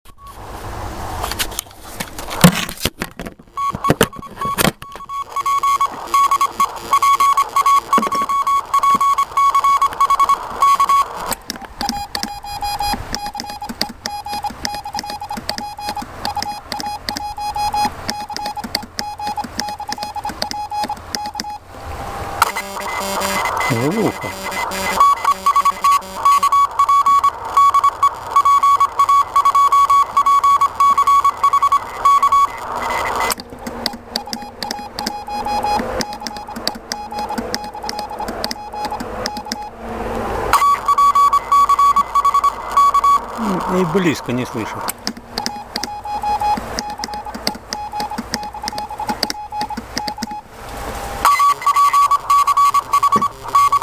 ТЕМА: QSO на самоделках
Выходил в поля- леса поработать честными 5-ю ваттами в тесте "Kulikovo Polye Contest".